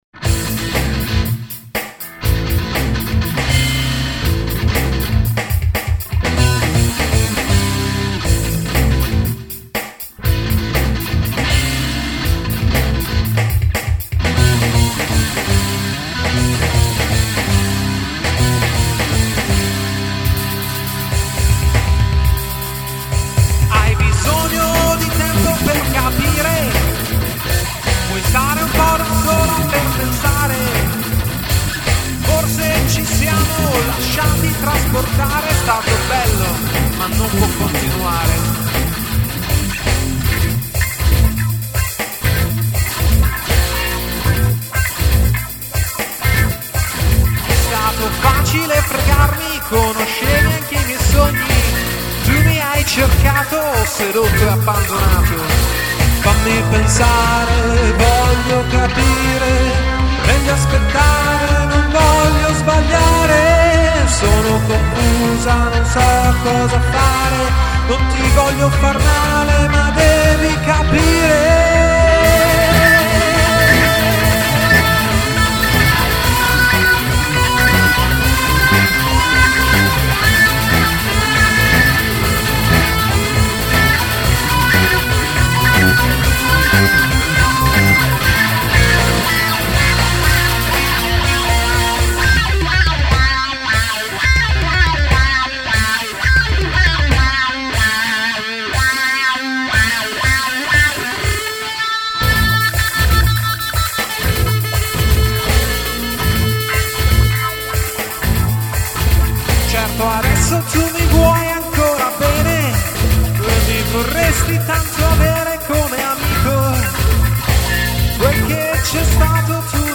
Basso, Chitarre e Programming